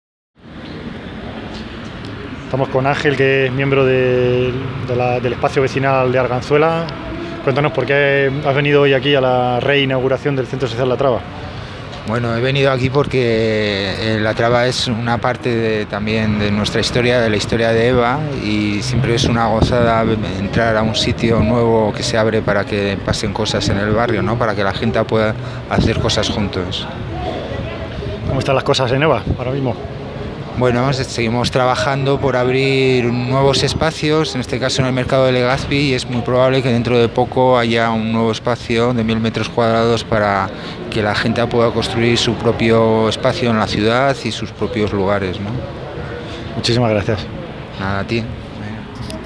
Entrevistas durante la re-inauguración del CSO La Traba
Entrevista